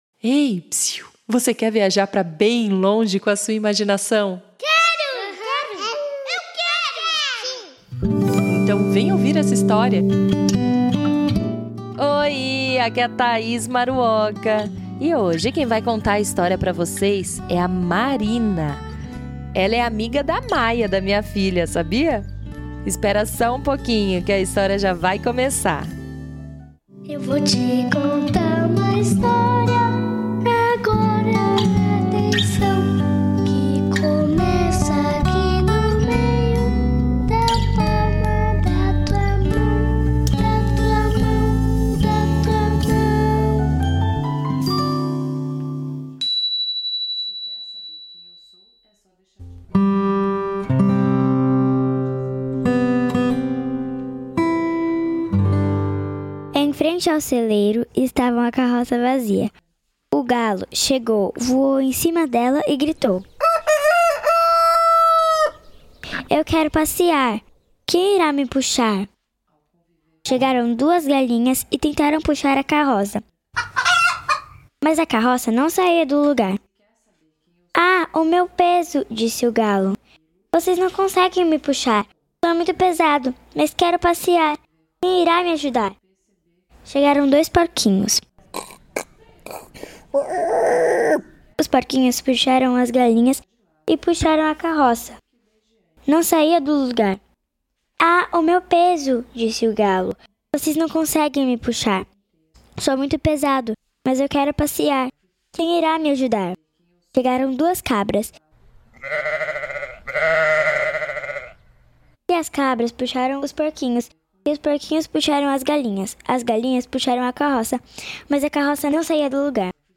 Nessa semana trouxemos um conto rítmico para as crianças! A história é sobre um galo que queria passear em uma carroça, mas nenhum animal conseguia ajudá-lo a empurrar a carroça!